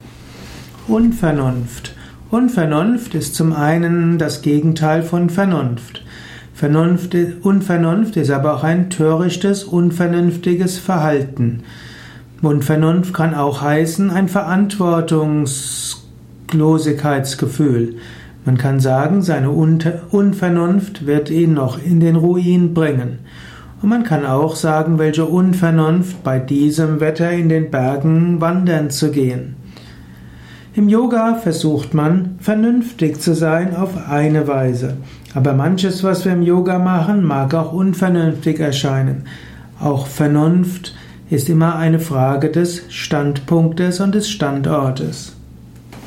Unvernunft - was bedeutet das? Erfahre einiges über Unvernunft in einem kurzen Spontan-Audiovortrag.
Dieser Audio Podcast über \" Unvernunft \" ist die Tonspur eines Videos, zu finden im Youtube Kanal Persönlich